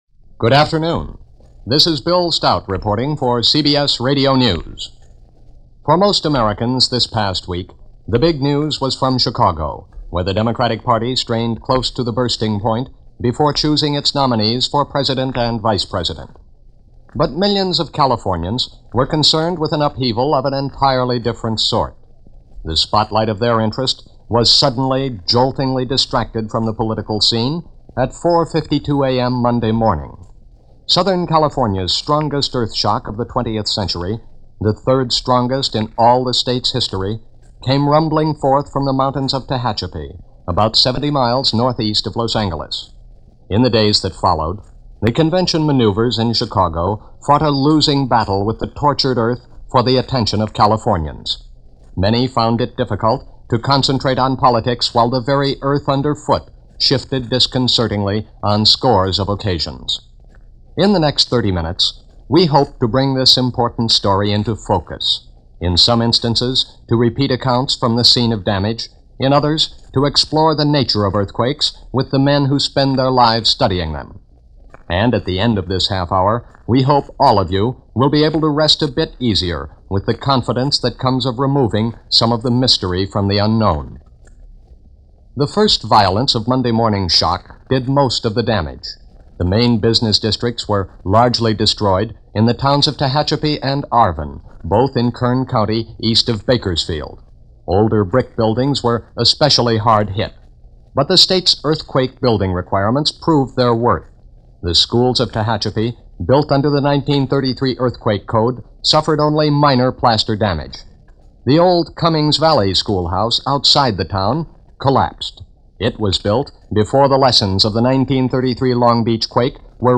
Sudden California - The Earthquake Story - July 27, 1952 - Produced by KNX Radio in Los Angeles - The big Earthquake of 1952.